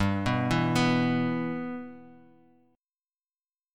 G6 Chord
Listen to G6 strummed